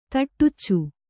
♪ taṭṭuccu